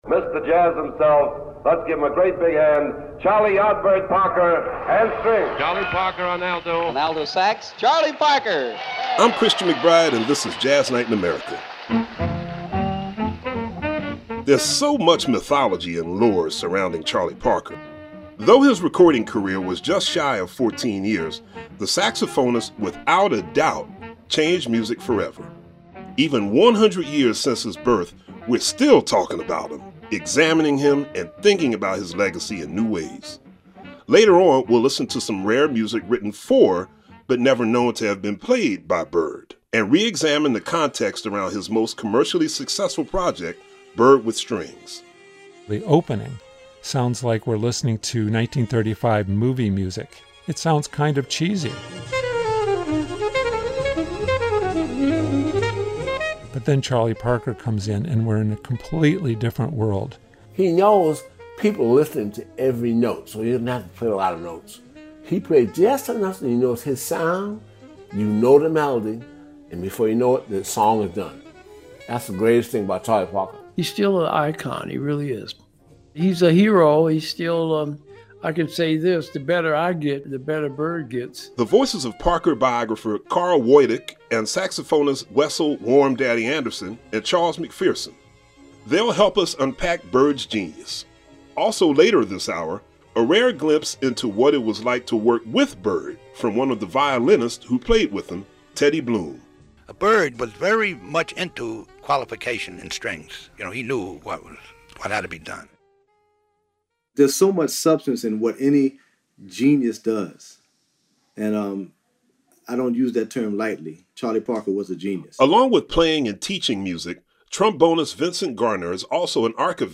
We'll examine the backstory and hear rare selections from the collaboration.